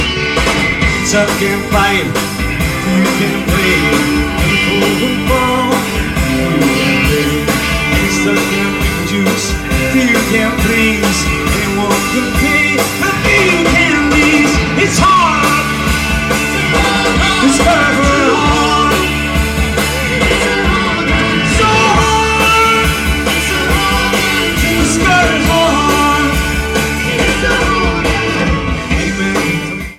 Comments: Very good mono soundboard recording*.